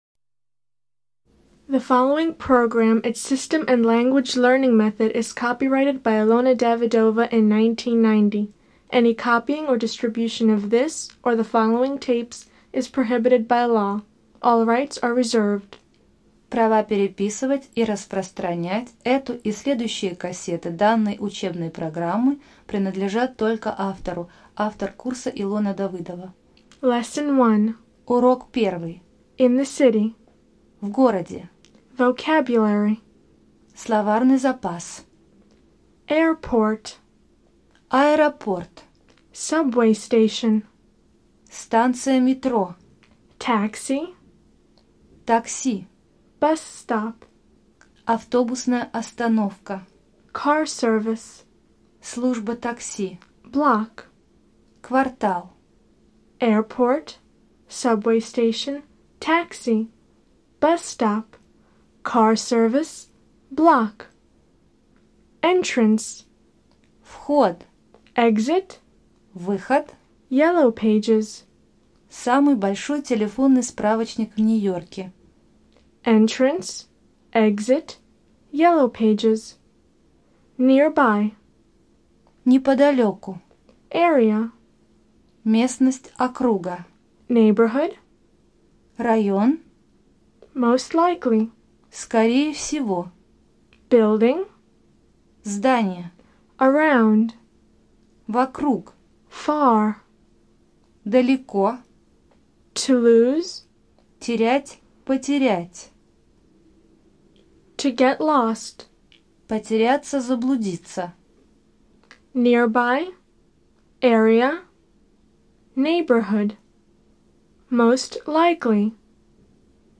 Аудиокнига Разговорно-бытовой английский. Курс 1. Диск 1. В городе.